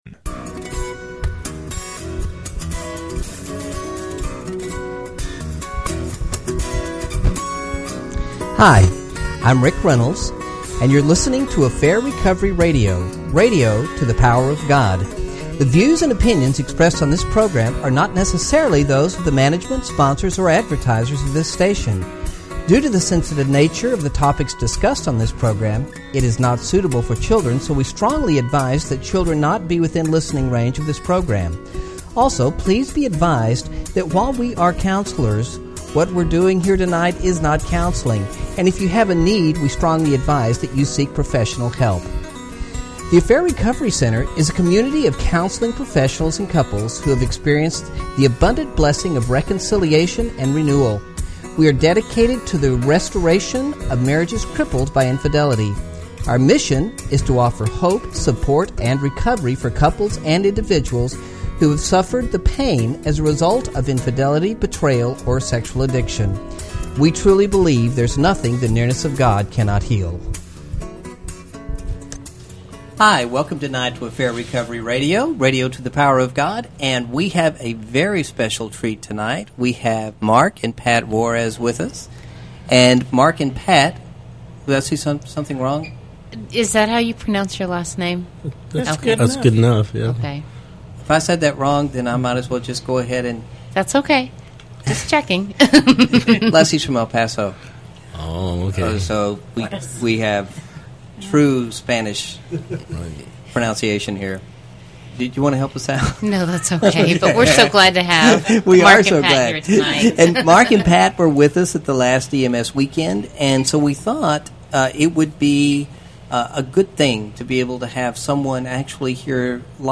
Hear about the EMS experience from a chaplain couple who recently attended.